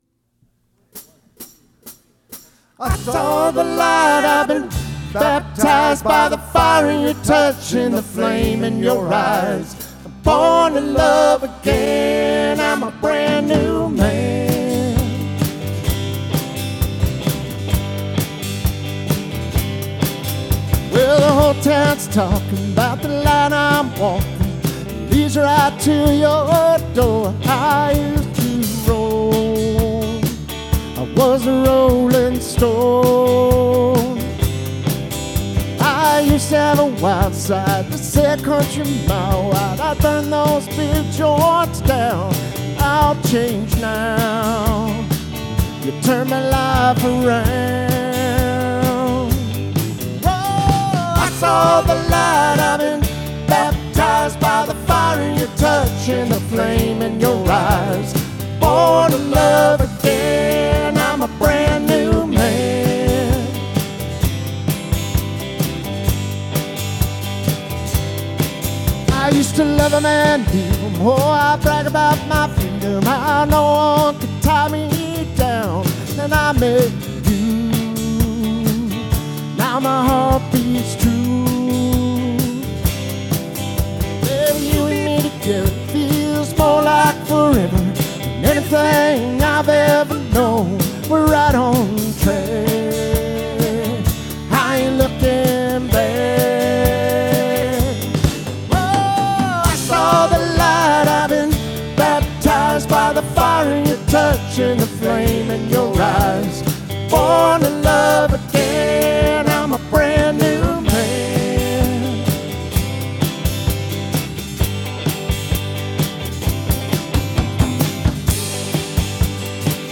Recorded Dec. 7, 2025 at the McMinnville Grange Hall
Live Audio Recordings
(All Recorded Live with no Overdubs)